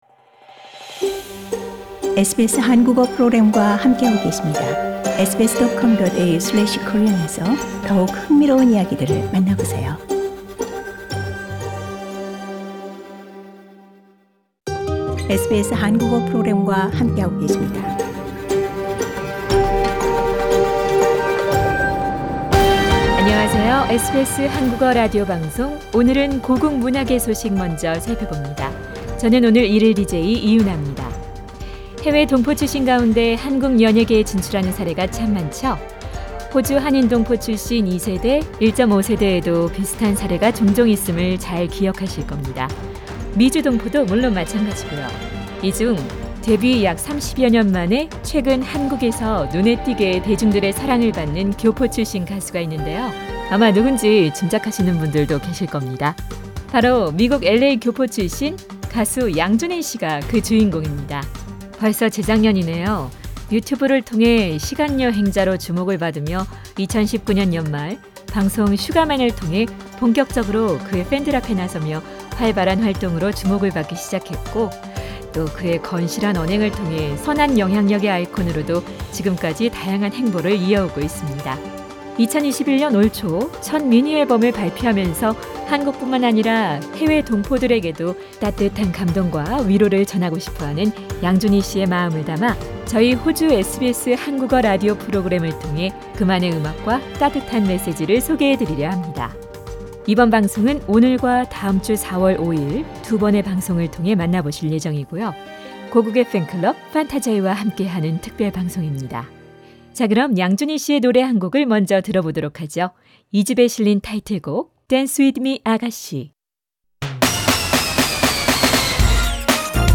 고국의 팬클럽 판타자이와 함께하는 특별방송입니다.